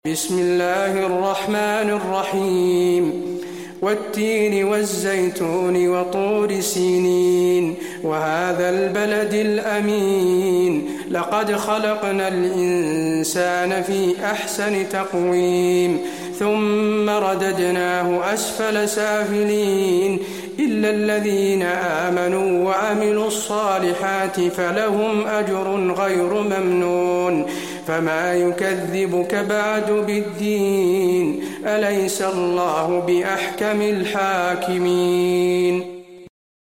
المكان: المسجد النبوي التين